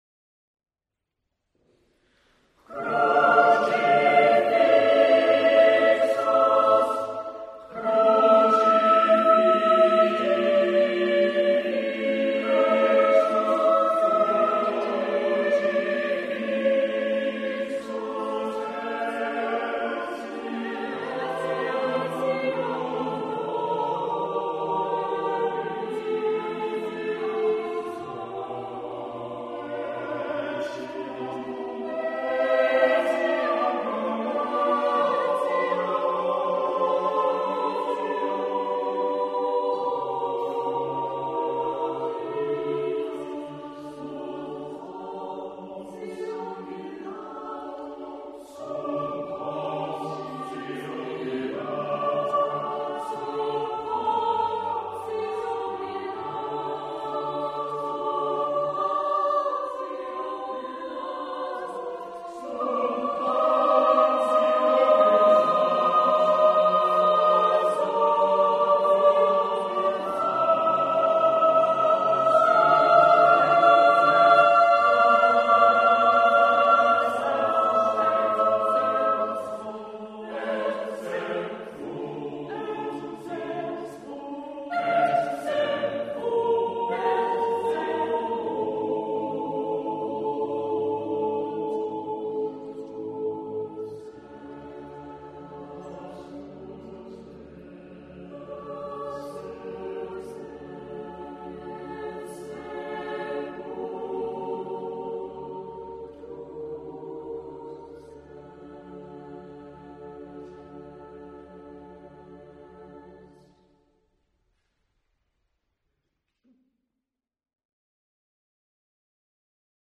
Cherwell Singers Concert March 2007